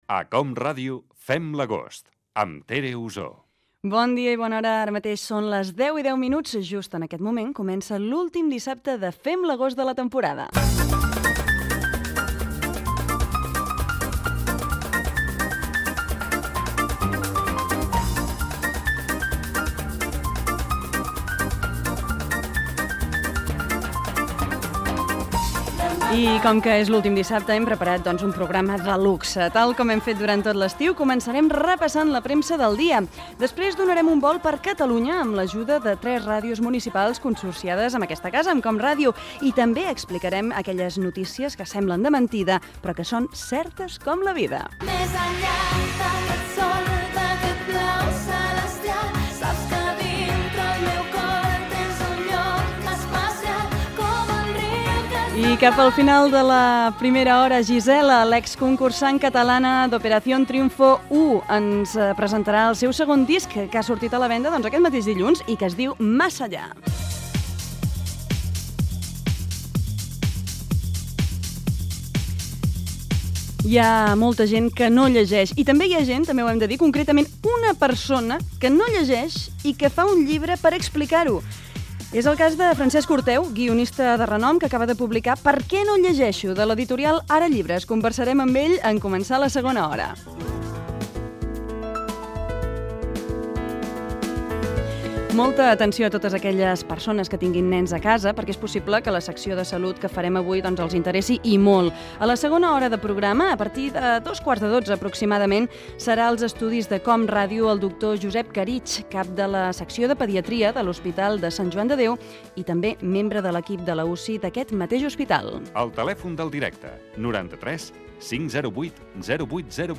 Indicatiu del programa, inici, sumari i crèdits del darrer programa de la temporada d'estiu. Indicatiu. Lectura de titulars de premsa.
Entreteniment